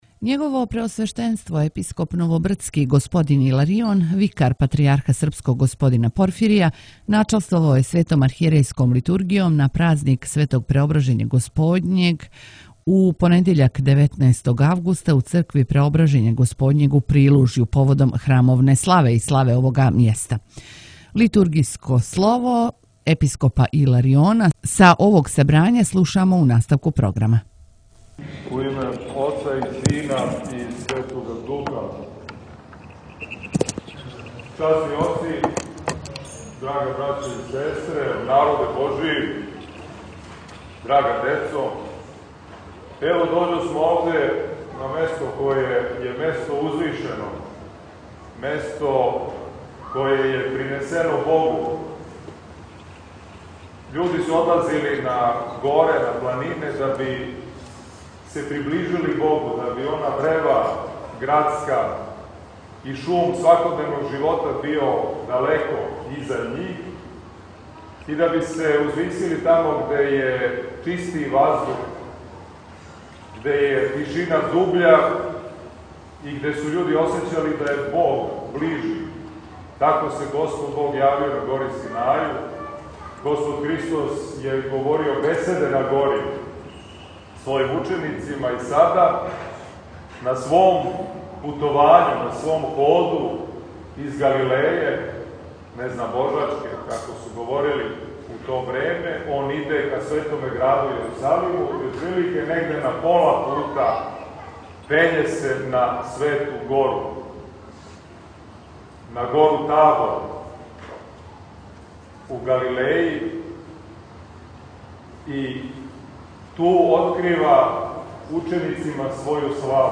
На празник Преображења Господњег, 19. августа 2024. године у Прилужју торжествено и молитвено прослављена је храмовна слава и слава овог места.
Поучавајући сабране беседом, Преосвећени Епископ је рекао да треба да дођемо до таквог стања да кажемо – добро нам је овде бити, а не да бежимо од онога где је тежина.